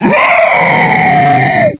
zo_idle02.wav